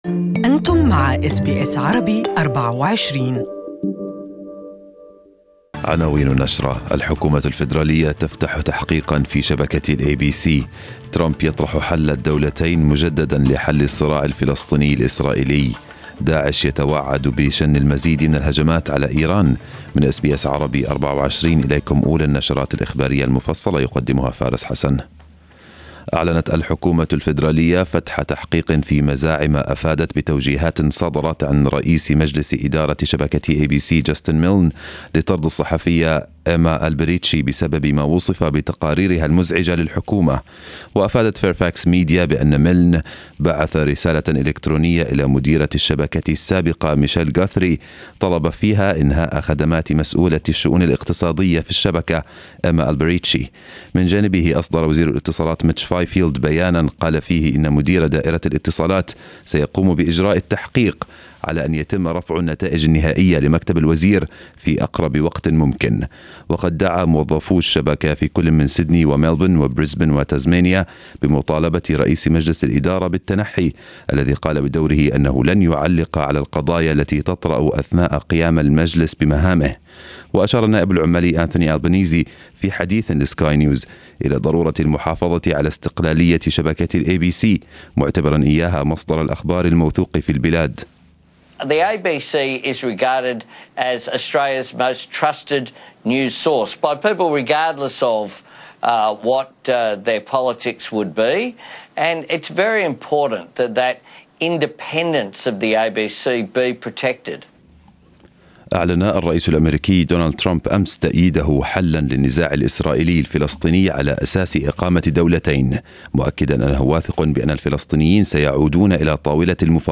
نشرتنا الإخبارية المفصلة لهذا الصباح